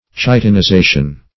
Chitinization \Chi`ti*ni*za"tion\, n. The process of becoming chitinous.
chitinization.mp3